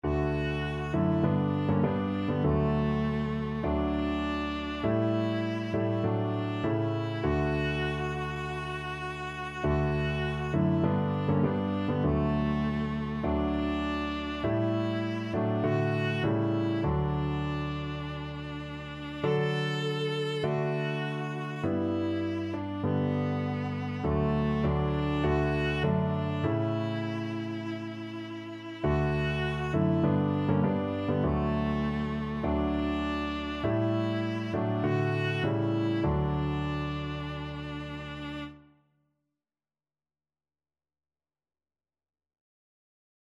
4/4 (View more 4/4 Music)